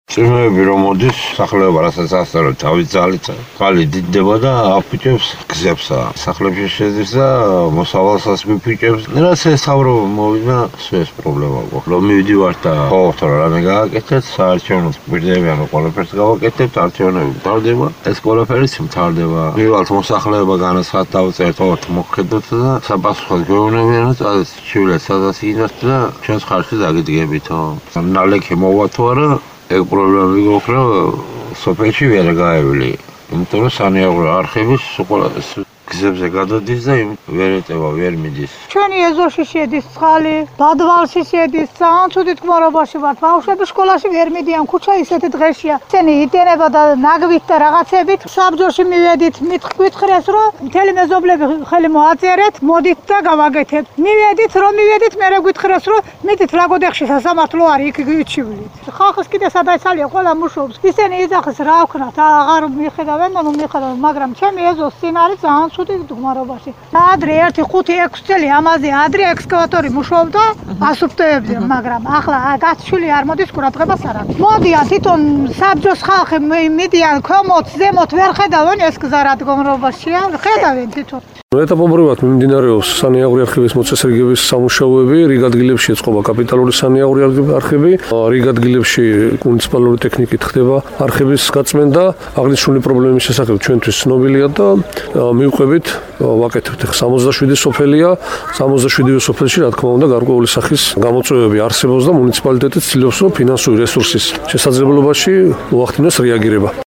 მოისმინეთ, კაბალელების და ლაგოდეხის მუნიციპალიტეტის მერის,ჯონდო მდივნიშვილის ხმები